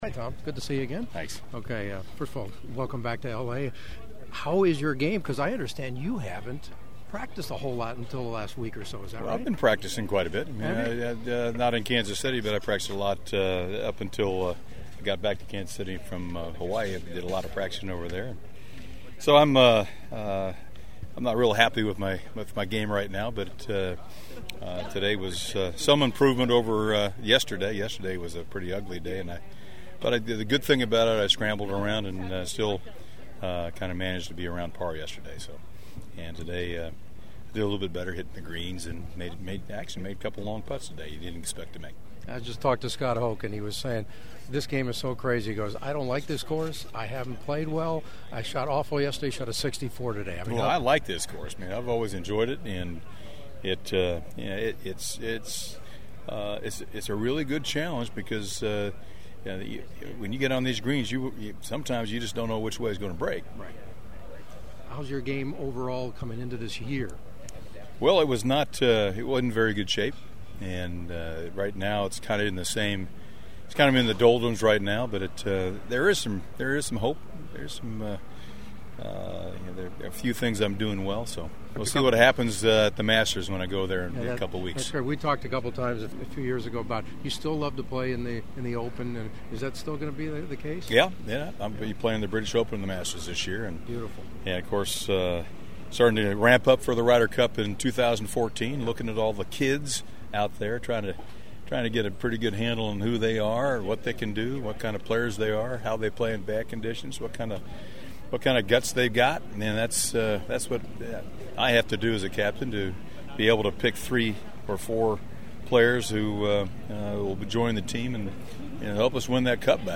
Hanging with my own age at the Champions Tour’s Toshiba Classic in Newport Beach